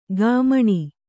♪ gāmaṇi